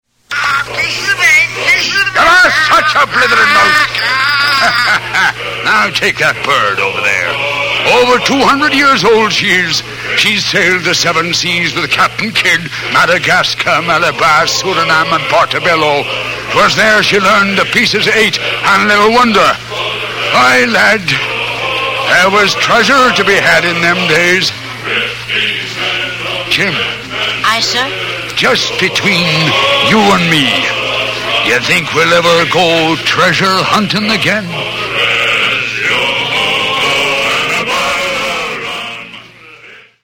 Basil Rathbone narrates Treasure Island and Robin Hood -- one 33.3 rpm record, P13895.